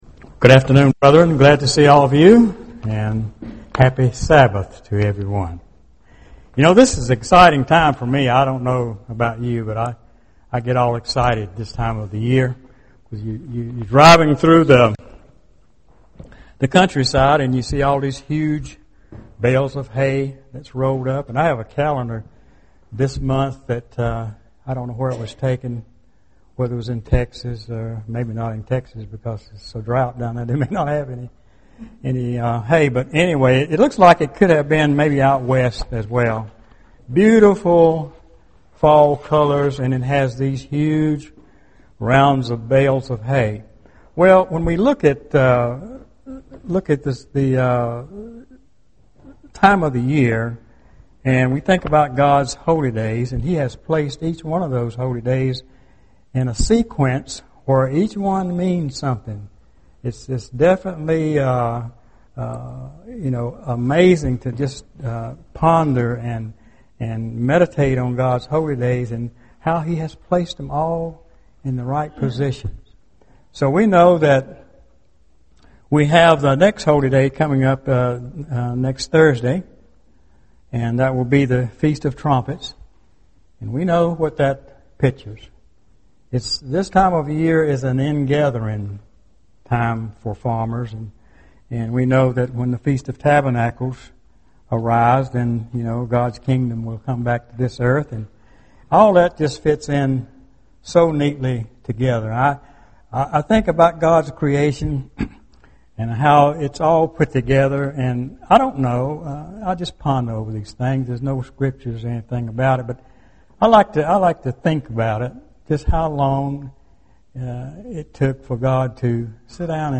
UCG Sermon Studying the bible?
Given in Hickory, NC